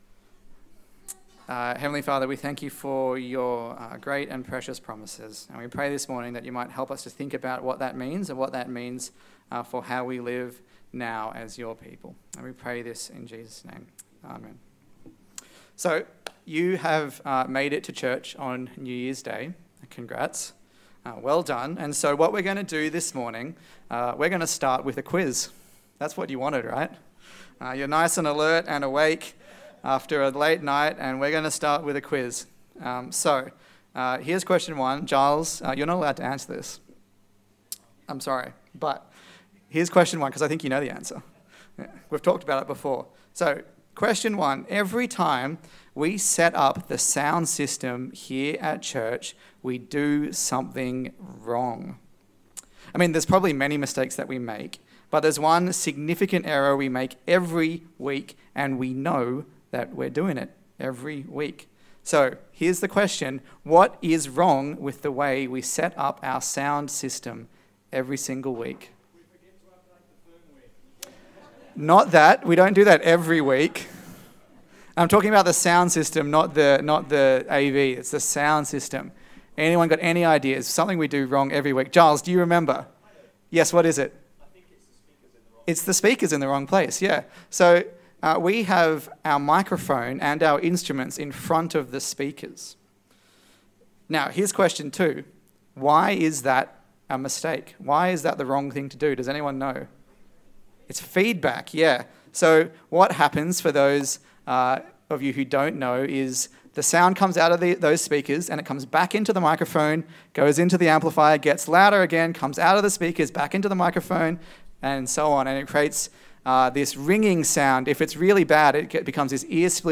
2 Peter Passage: 2 Peter 1:1-11 Service Type: Sunday Service